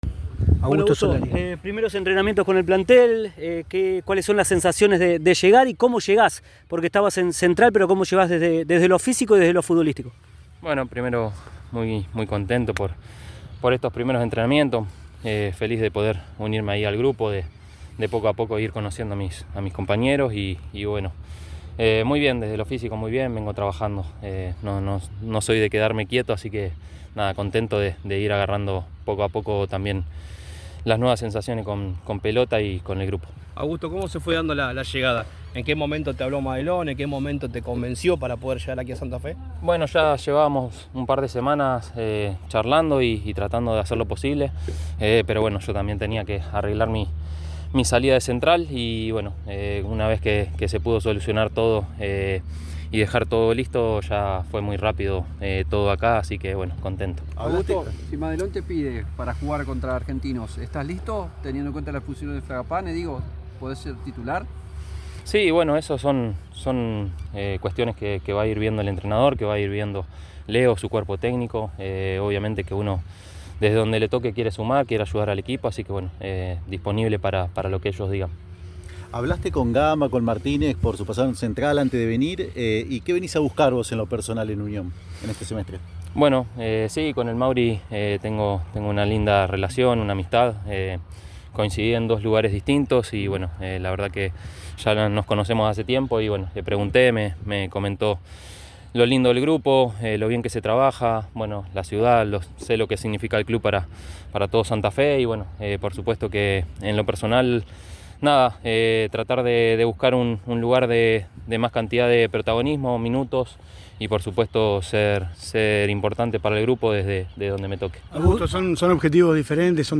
En lo físico me siento muy bien, siempre me mantuve entrenando”, afirmó Solari, ante los micrófonos de EME, en su primera charla con los medios de Santa Fe.